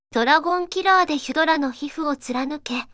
voicevox-voice-corpus